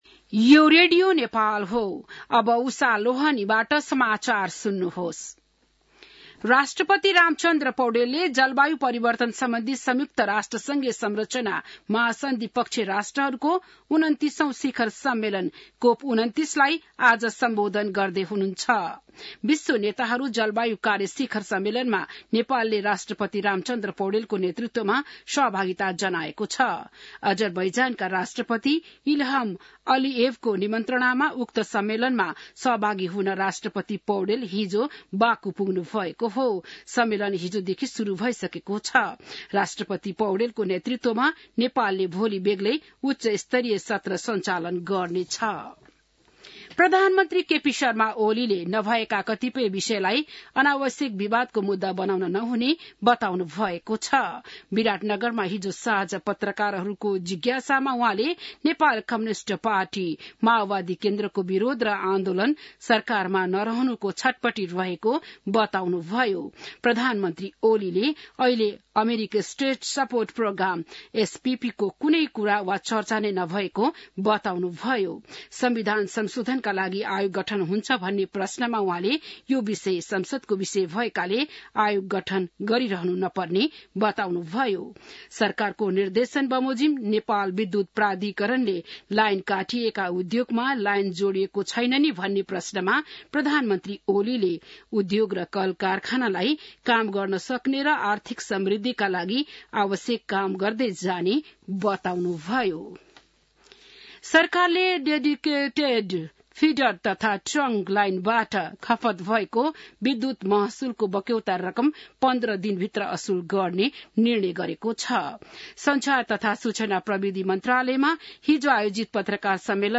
बिहान १० बजेको नेपाली समाचार : २८ कार्तिक , २०८१